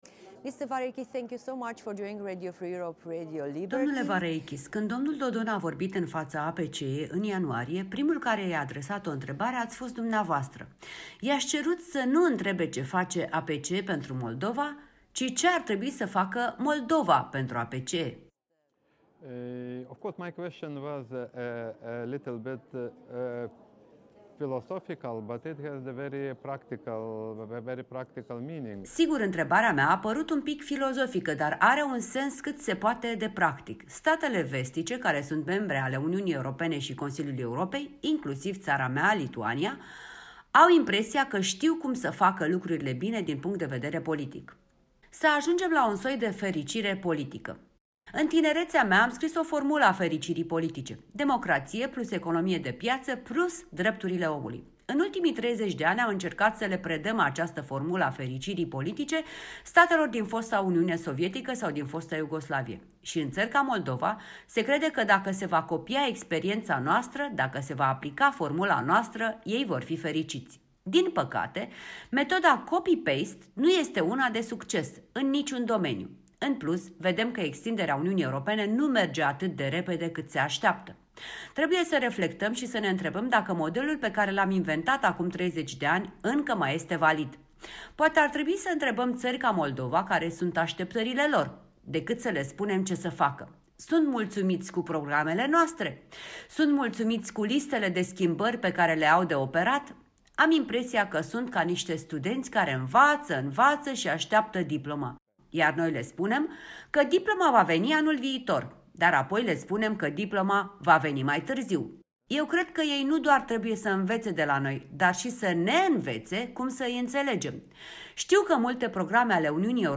Un interviu cu co-raportorul APCE pentru Moldova, Egidijus Vareikis (PPE- Lituania)